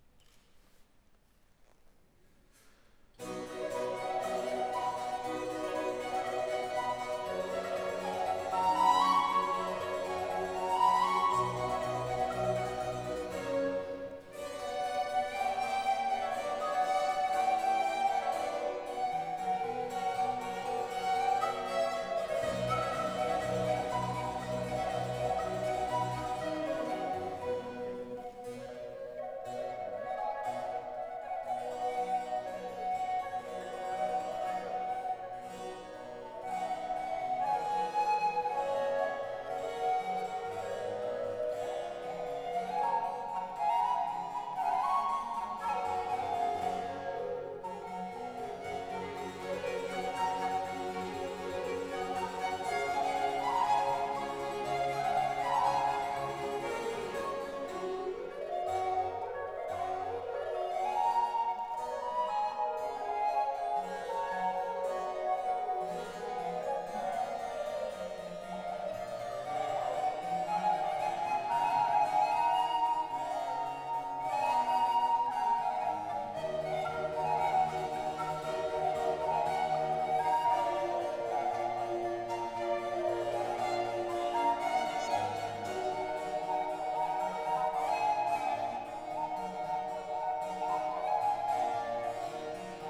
violon
traverso
flûte de voix
flûte à bec basse
clavecin
Genre : Musique Baroque.
Extrait-La-Camerata-Chiara-Vivaldi-Concerto-en-la-mineur-concert-Polyfolies-2020.wav